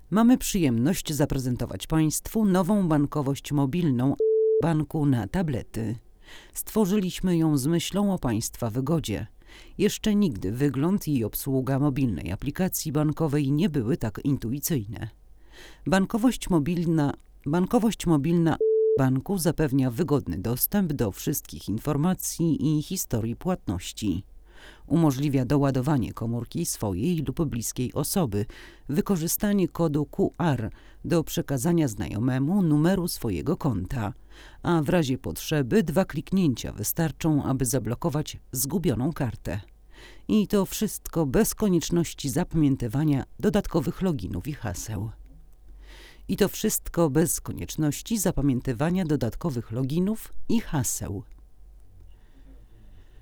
Zawsze nagrywamy lektorów w studiu Mikrofoniki, i zawsze stosujemy do porównań ten sam tor nagraniowy.
Nagranie wokal żeński
Co prawda automatycznie dostaliśmy do sygnału więcej niechcianego szumu, ale jest to szum równy, gładki, stabilny.
Bardzo przyjemne dla ucha, nasycone, zrównoważone, wydaje się naprawdę predysponowane do głosów lektorskich.